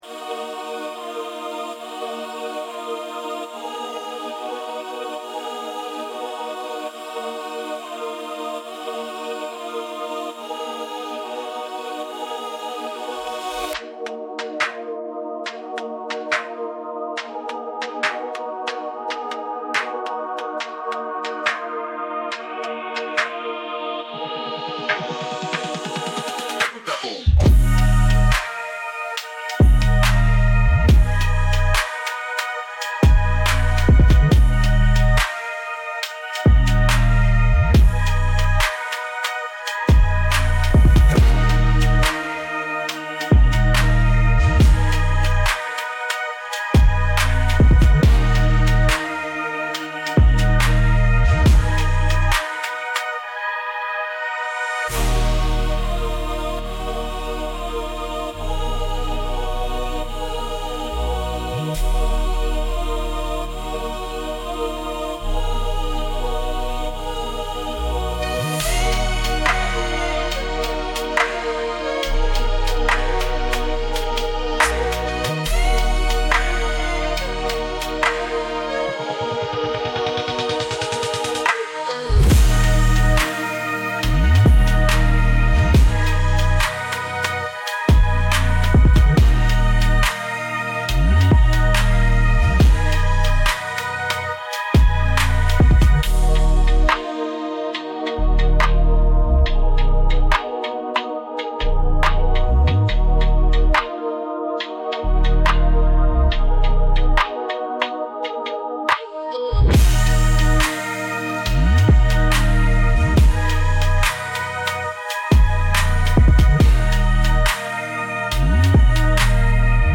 Dark - intense - 2.19 .mp3